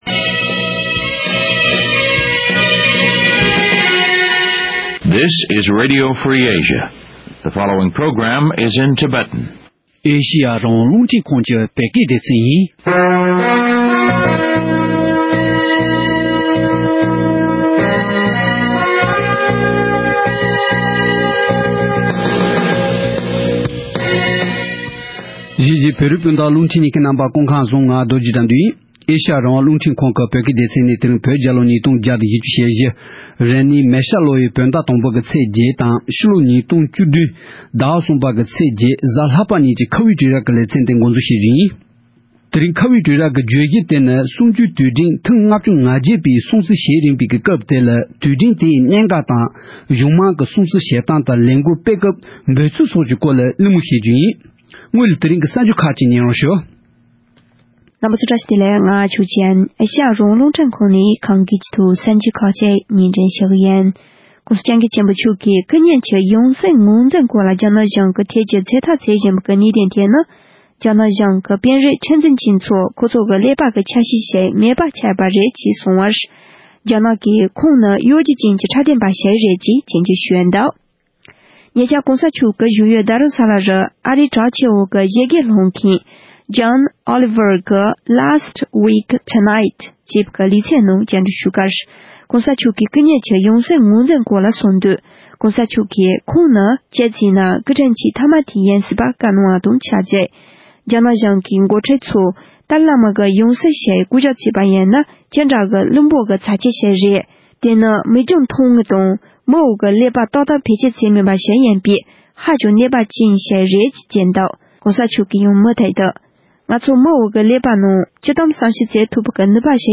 བཙན་བྱོལ་སྤྱི་ཚོགས་ནང་གཞུང་མང་གཉིས་ཀྱི་སྲུང་བརྩི་བྱེད་སྟངས་དང་། ལས་འགུལ་སྤེལ་སྐབས་ཕྲད་དར་དང་འབོད་ཚིག་སོགས་ཀྱི་སྐོར་འབྲེལ་ཡོད་ཁག་ཅིག་དང་གླེང་མོལ་ཞུས་པ་གསན་རོགས་གནང་།